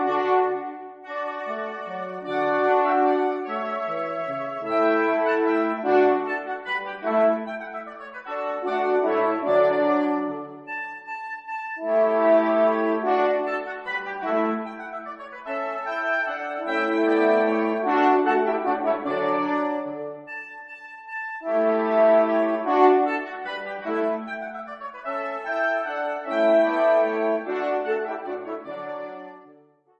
2 oboes, 2 horns, bassoon
(Audio generated by Sibelius/NotePerformer)